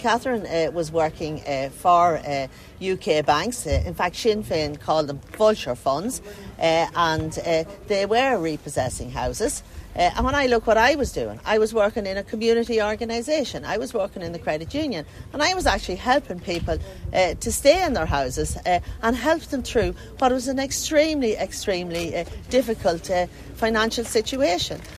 However at a doorstep in County Roscommon this lunchtime, the Fine Gael candidate has hit back at her rival saying deputy Connolly needs to say who she was working for and how many homes were repossessed: